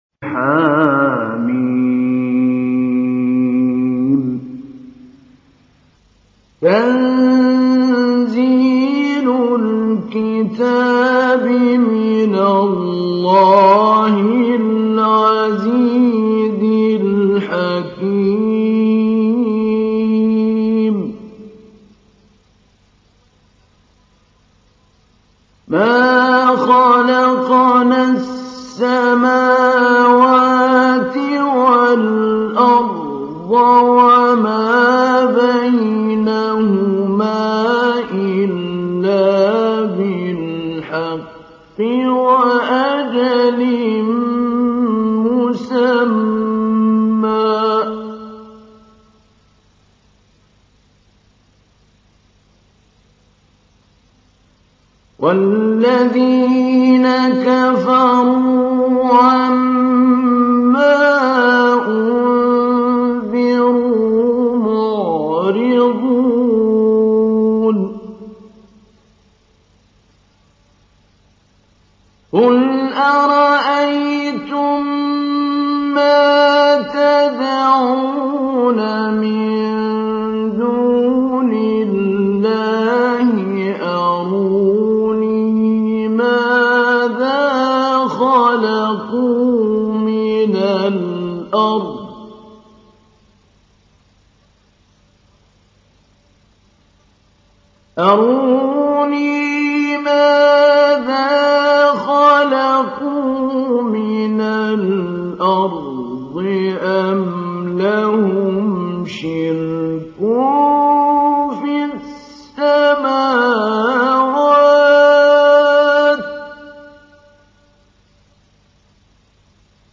تحميل : 46. سورة الأحقاف / القارئ محمود علي البنا / القرآن الكريم / موقع يا حسين